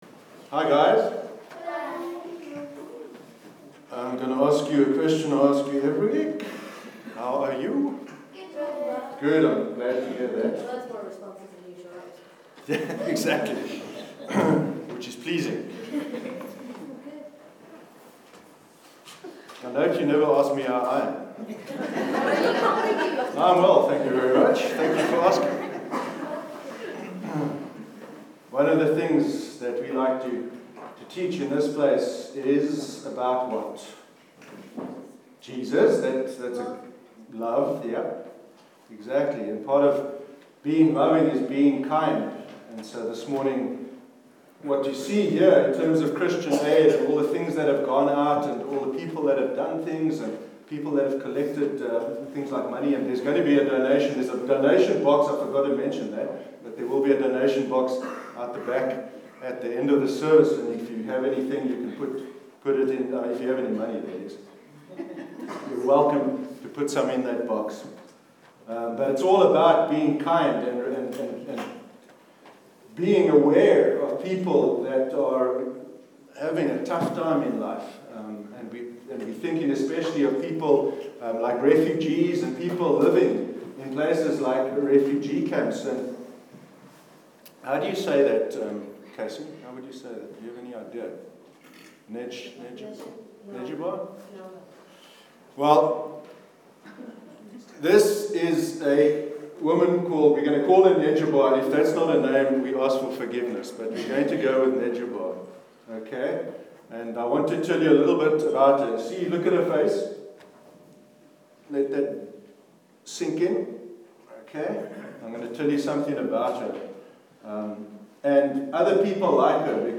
Children’s Address- Christian Aid Week 21st May 2017